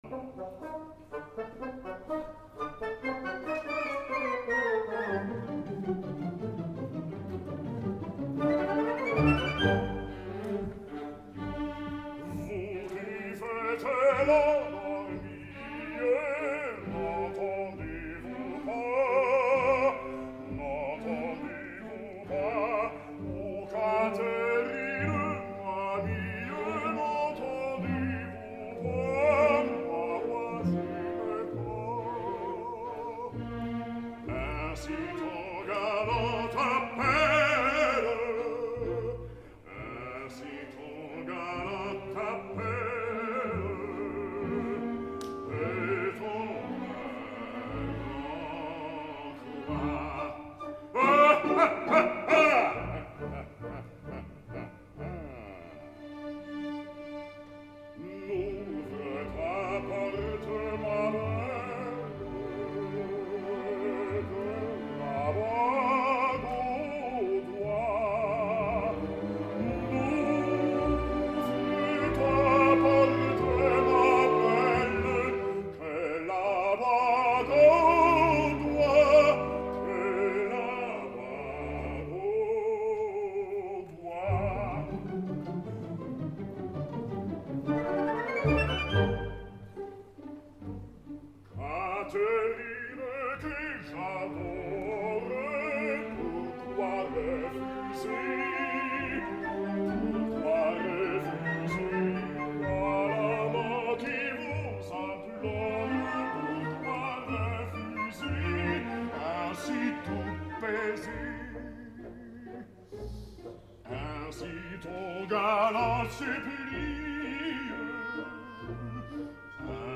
Méphistophélès: René Pape
Escoltem al baix alemany en “Vous qui faites l’endormie” de l’acte quart.